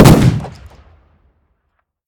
shotgun-shot-6.ogg